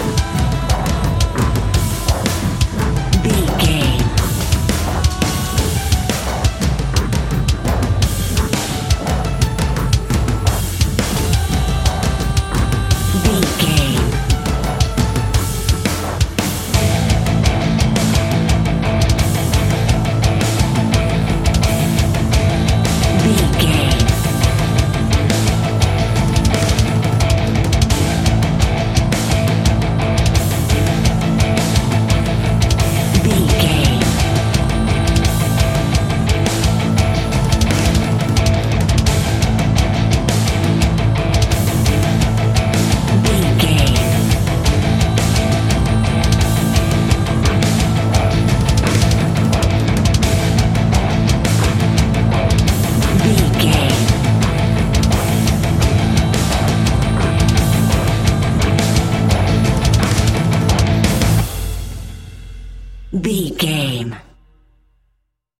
Epic / Action
Fast paced
Aeolian/Minor
heavy metal
Heavy Metal Guitars
Metal Drums
Heavy Bass Guitars